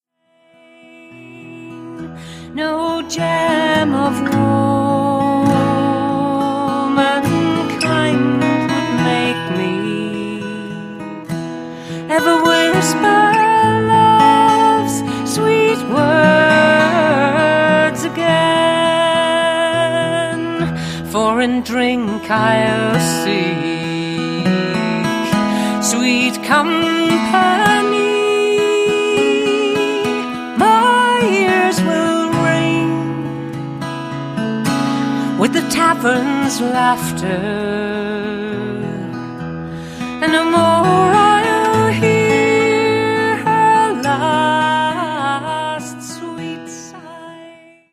dreamy guitar makes it a soulful song of loss